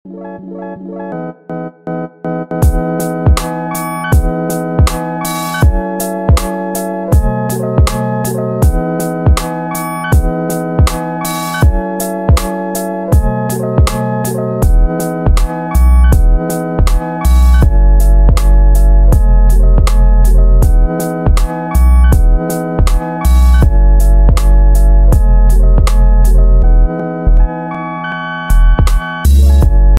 Kategorien Soundeffekte